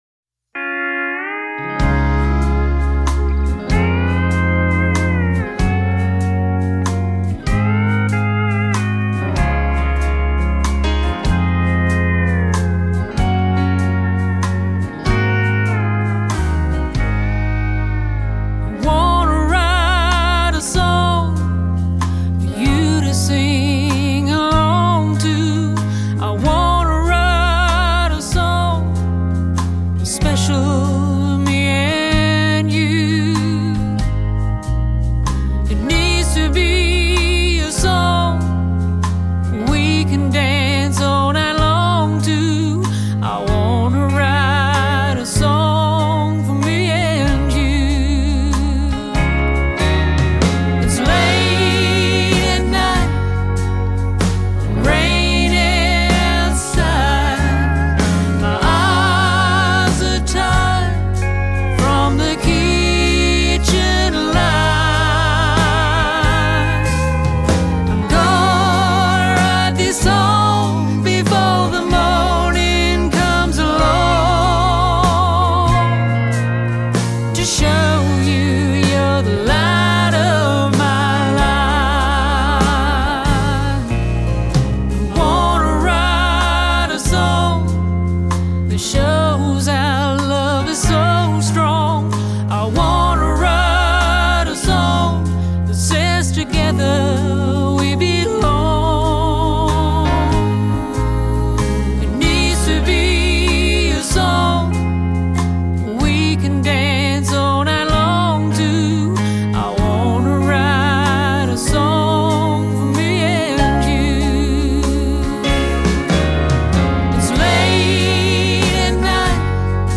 husband and wife duo
beautiful country waltz ballad